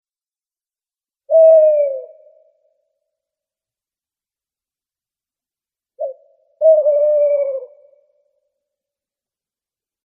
Ringetone Ugle
Kategori Dyr
ugle.mp3